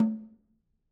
Snare2-HitNS_v3_rr2_Sum.wav